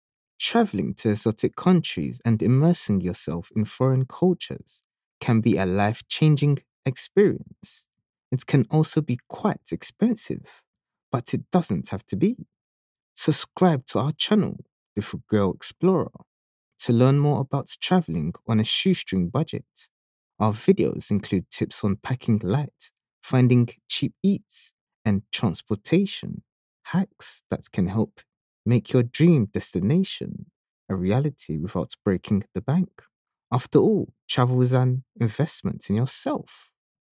Never any Artificial Voices used, unlike other sites.
Explainer & Whiteboard Video Voice Overs
English (Caribbean)
Yng Adult (18-29) | Adult (30-50)